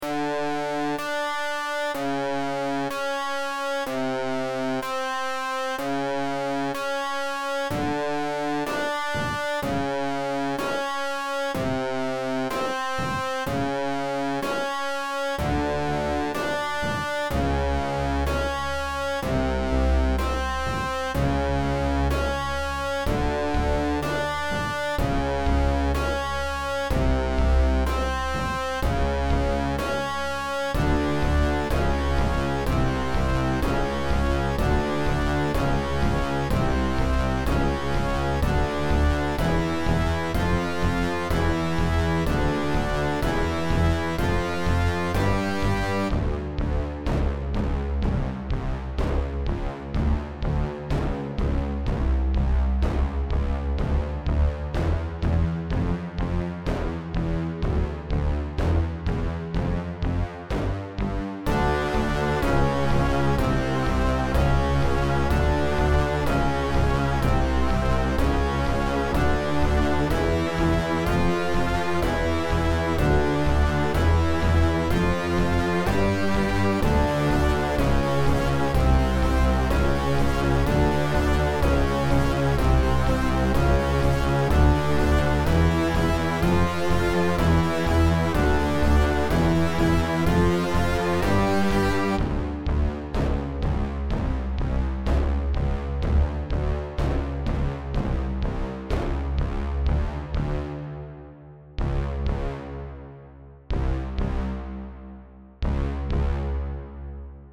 Could be the theme of a game area with lots of machinery If you want to modify it, download the beepbox file